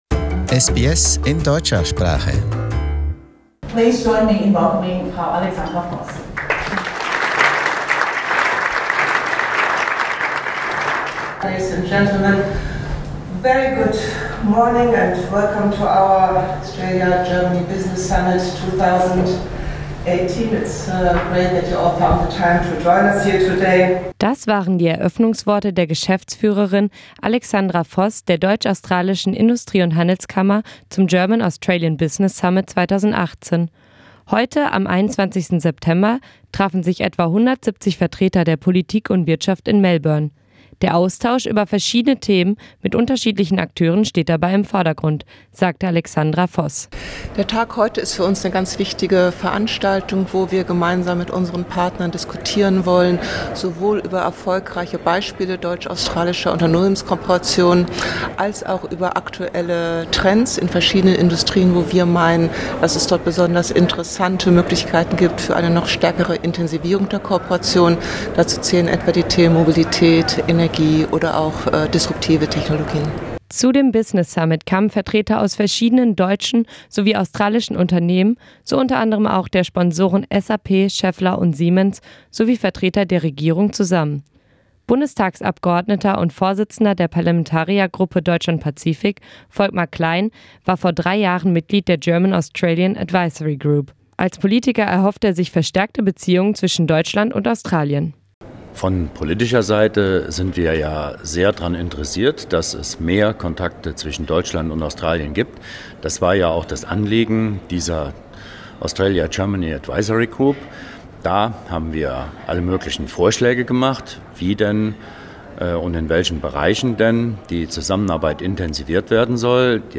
Warum ist jetzt die richtige Zeit um deutsch-australische Beziehungen zu stärken? Und welche Vorteile kann das Freihandelsabkommen bringen? Beim Business Summit 2018 in Melbourne am vergangenen Freitag wurden wichtige Fragen besprochen.